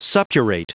Prononciation du mot suppurate en anglais (fichier audio)
Prononciation du mot : suppurate